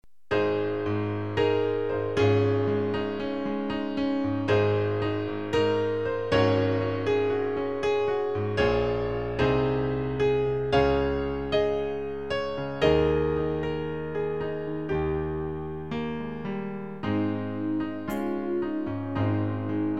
Klavier-Playback zur Begleitung der Gemeinde
MP3 Download (ohne Gesang)
Themenbereich: Jesuslieder